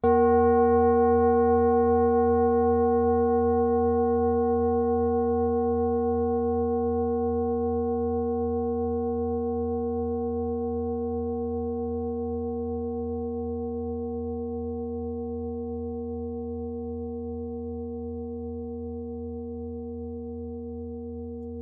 Klangschale Nepal Nr.57
(Ermittelt mit dem Filzklöppel)
klangschale-nepal-57.wav